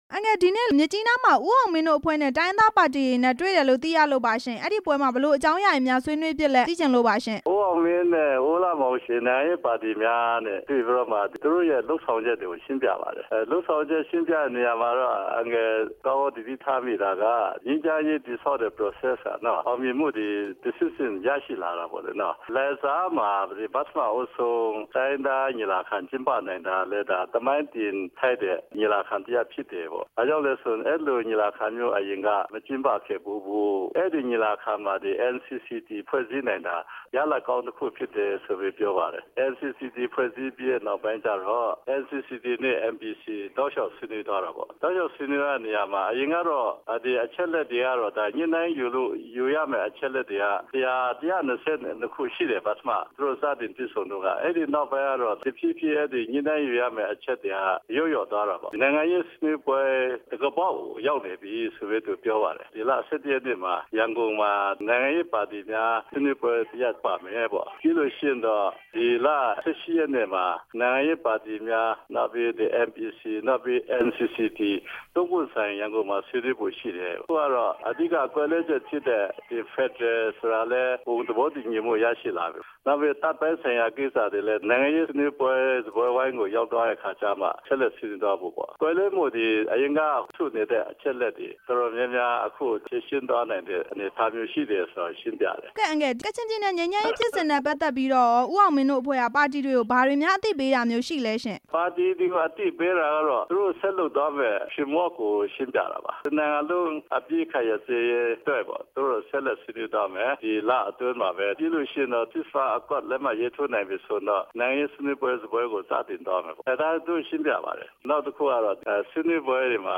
ဝန်ကြီး ဦးအောင်မင်းနဲ့ ကချင်နိုင်ငံရေးပါတီတွေ တွေဆုံပွဲ မေးမြန်းချက်